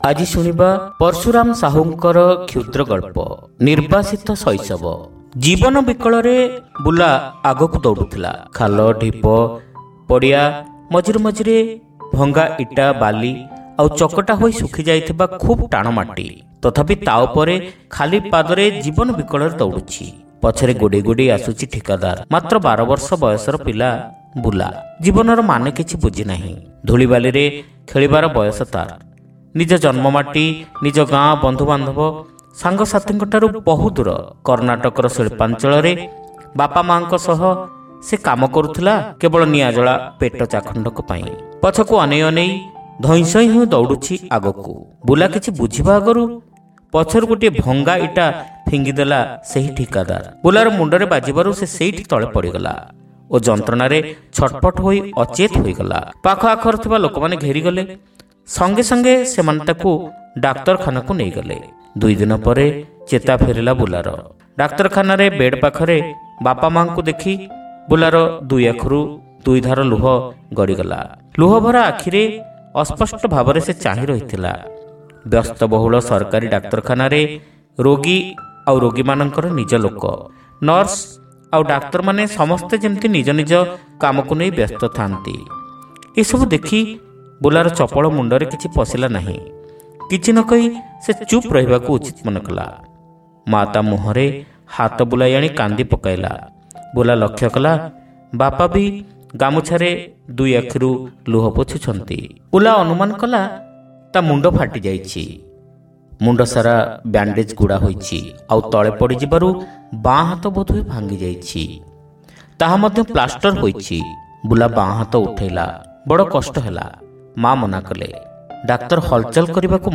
Audio Story : Nirbasita Shaishaba